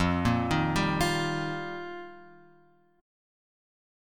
F9sus4 chord {1 1 1 0 x 1} chord